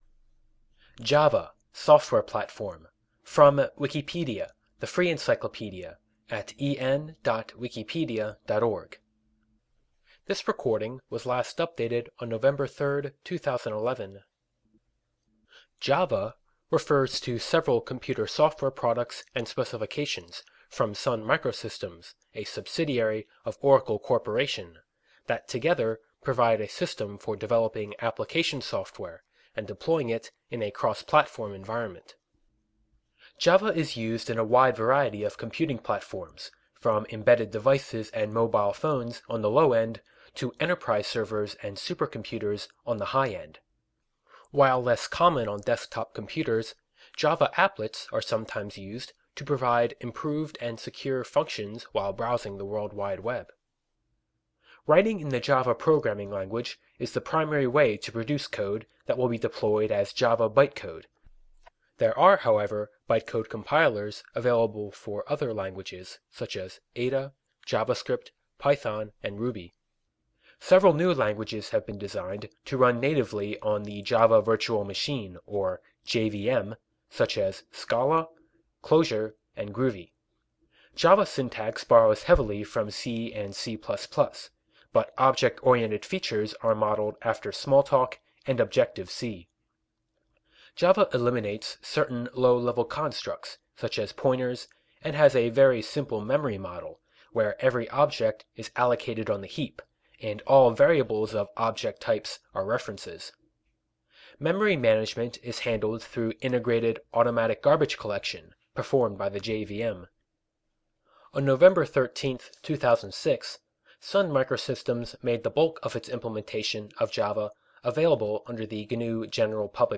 This is a spoken word version of the Wikipedia article: Java (software platform)Listen to this article (audio help)
Dialect/Accent InfoField American English Gender of the speaker InfoField Male
Source Own recording by the speaker